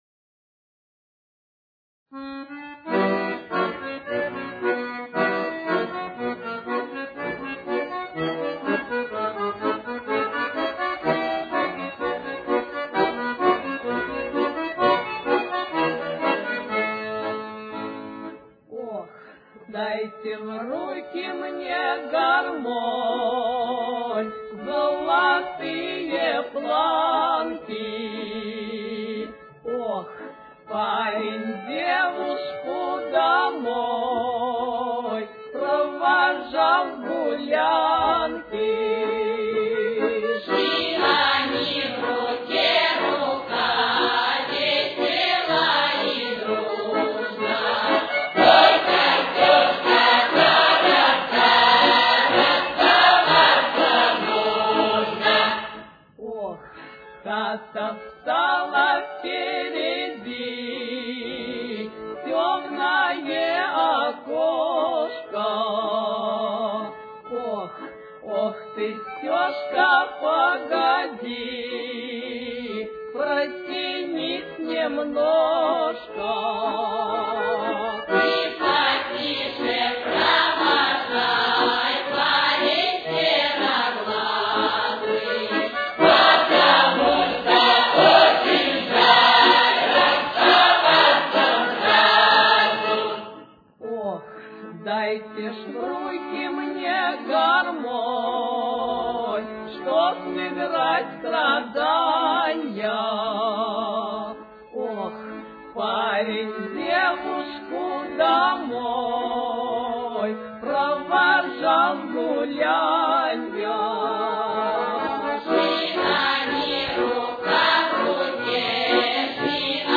Темп: 62.